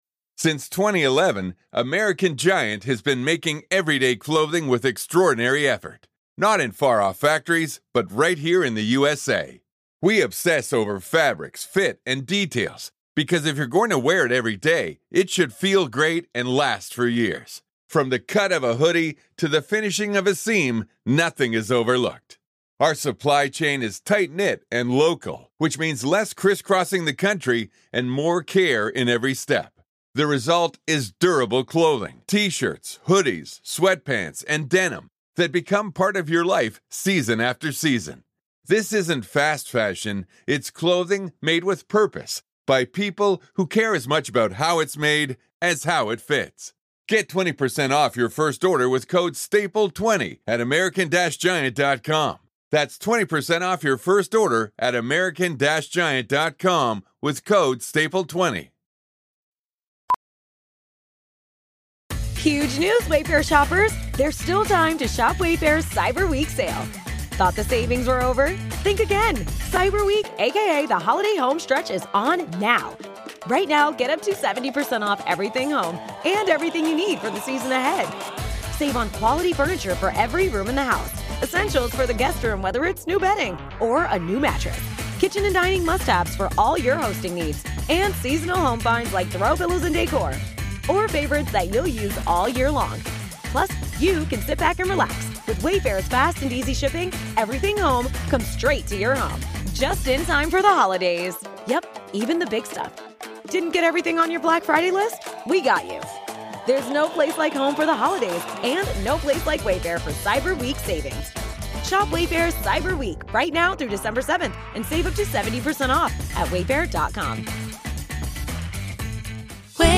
NWZ Nachrichten Botcast – der tägliche News-Podcast aus dem Norden